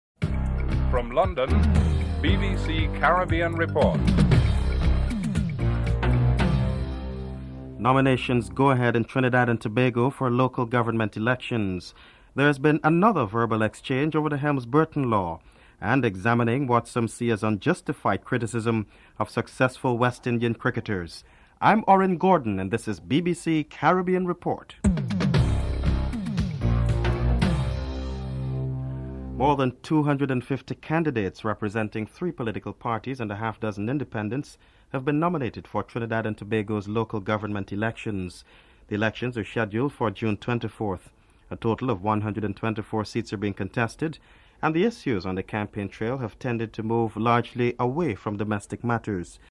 1. Headlines (00:00-00:27)
2. Nominations go ahead in Trinidad and Tobago for local government elections. Patrick Manning of the People's National Movement and Prime Minister Basdeo Panday are interviewed.